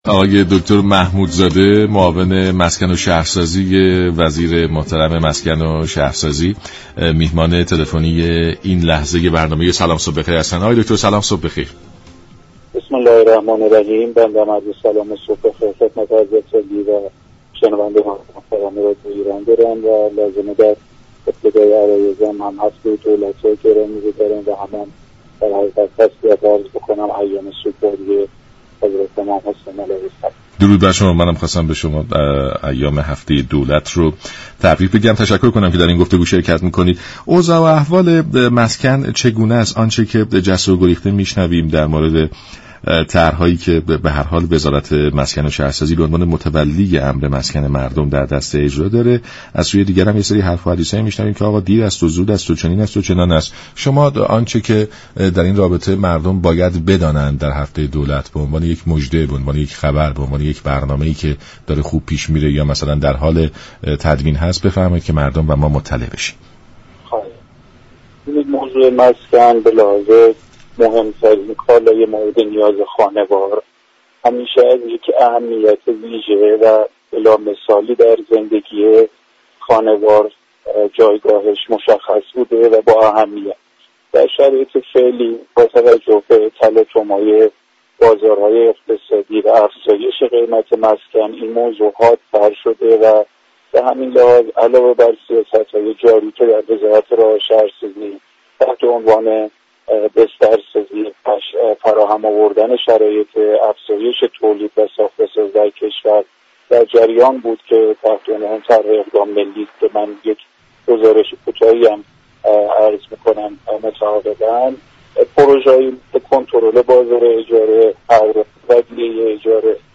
به گزارش شبكه رادیویی ایران، «محمود محمودزاده» معاون مسكن و ساختمان وزارت راه و شهرسازی در برنامه «سلام صبح بخیر» رادیو ایران درباره وضعیت بازار مسكن گفت: شرایط فعلی و تلاطم های بازارهای اقتصادی بر روند قیمت مسكن تاثیر بسزایی گذاشته است.